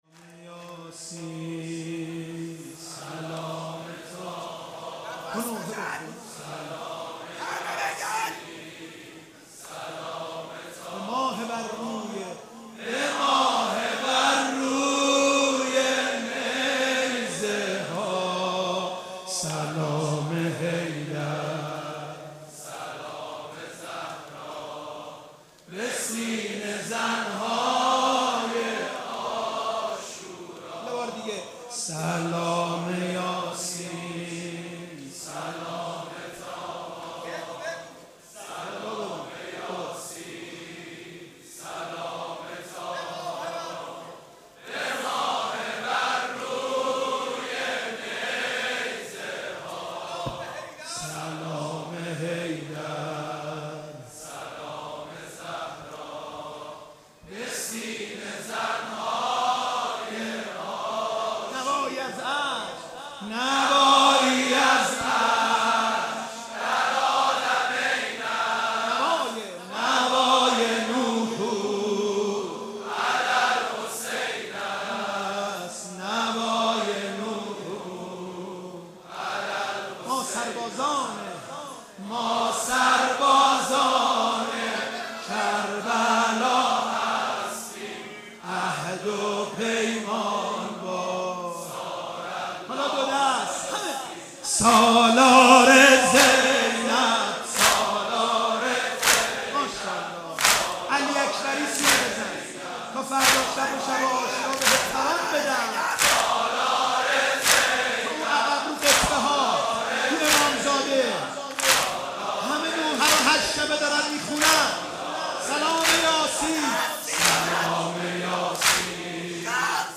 نوحه جدید